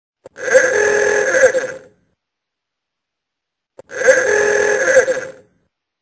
Apple iOS Old Car Horn
Apple-iOS-Ringtones-Old-Car-Horn.mp3